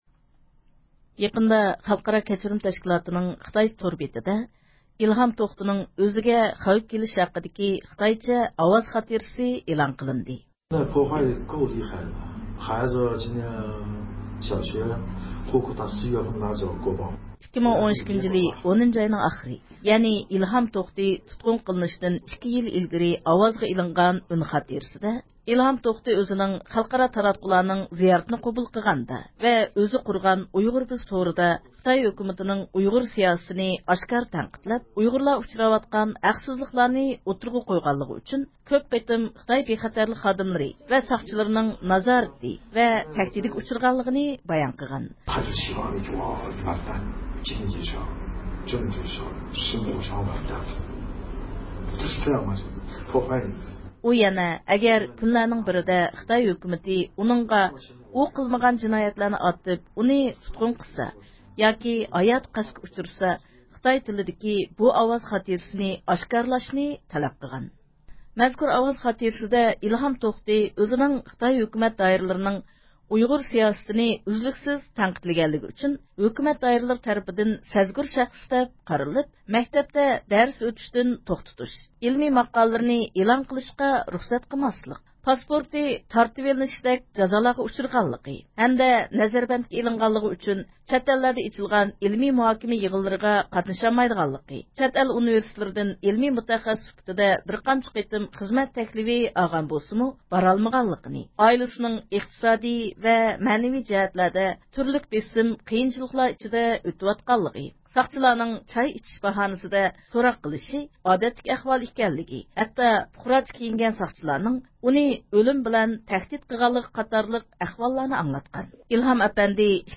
مەزكۇر ئاۋاز خاتىرىسىنىڭ مەزمۇنى ۋە ئىلھام توختىنىڭ ئۆزىنىڭ تەقىبگە ئۇچراۋاتقانلىقى ھەققىدە ئىلگىرى رادىيومىزغا قالدۇرغان ئۇيغۇرچە ئاۋاز خاتىرىلىرىنى ئاڭلايسىلەر.